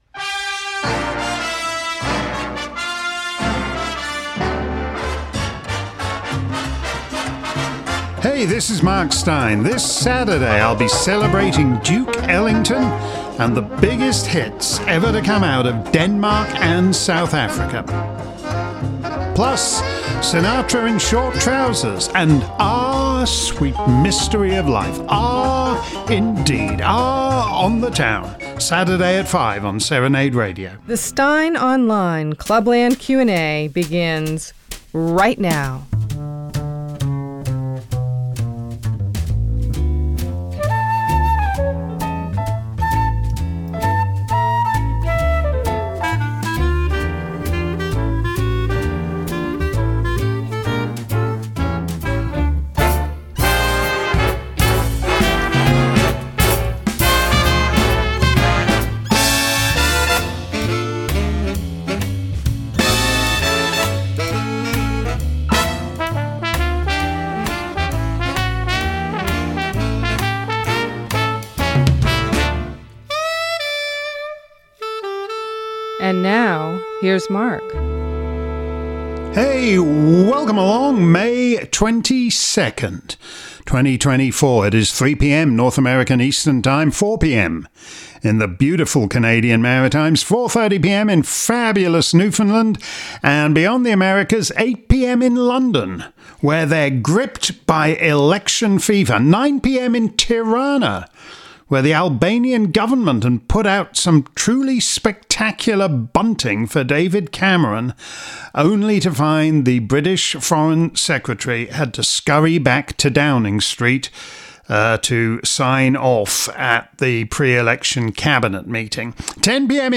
If you missed today's Clubland Q&A live around the planet, here's the action replay. Steyn was back at the microphone, fielding questions on many topics, starting with Rishi Sunak's hilariously sodden announcement of a general election and moving on to Klaus Schwab's successor at the WEF, Democrats' plans for Trump, Covid vaccine developments - and music for the chocolate soldiers of GB News.